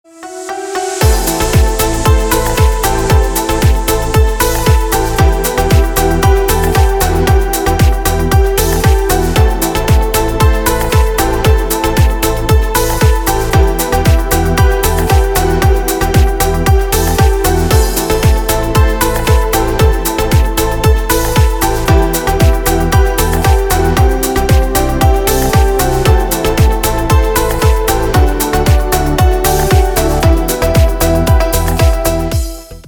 • Песня: Рингтон, нарезка
теги: красивый рингтон